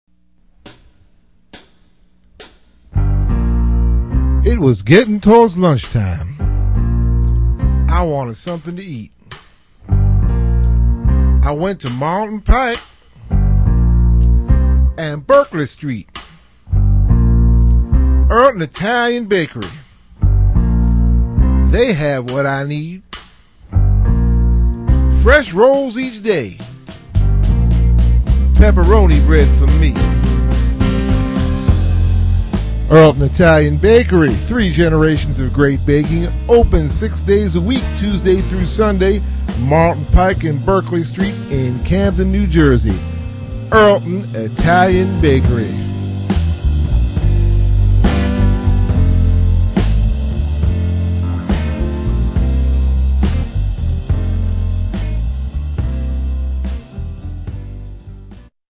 RADIO AD